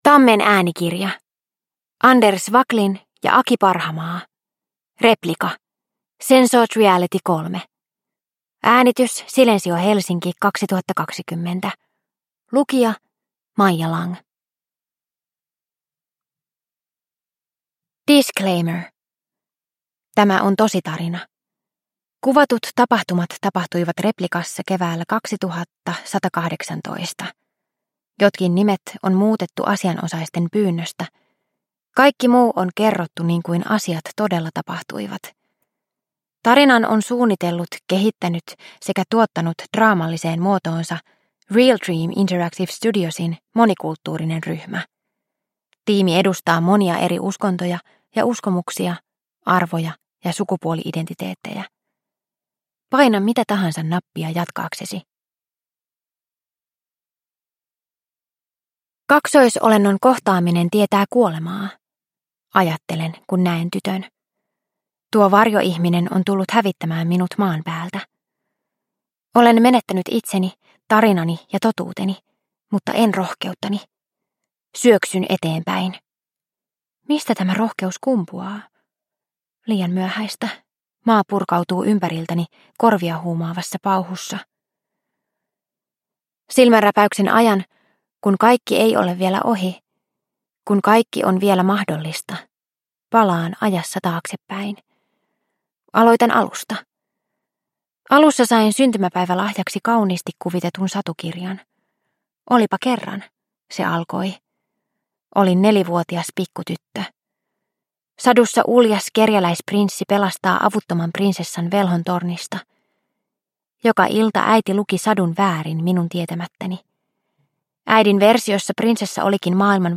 Replica. Sensored Reality 3 – Ljudbok – Laddas ner